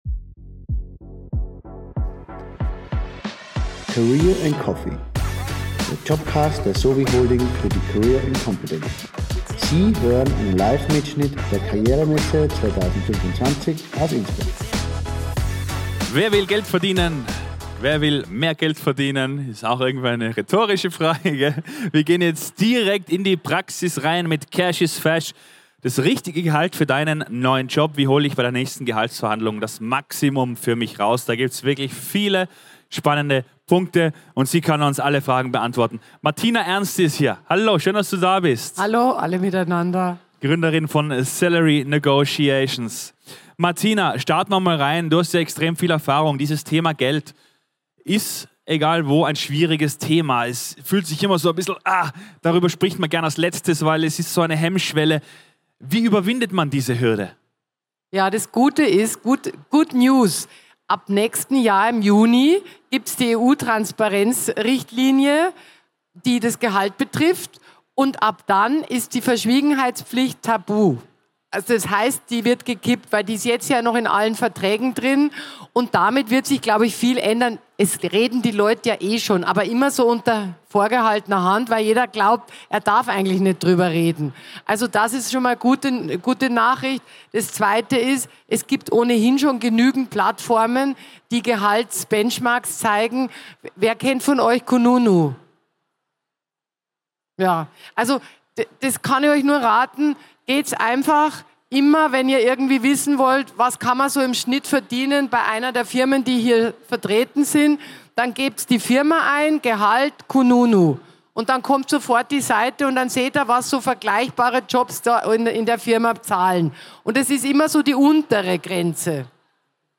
Livemitschnitt #5 von der career & competence am 14. Mai 2025 im Congress Innsbruck. 2.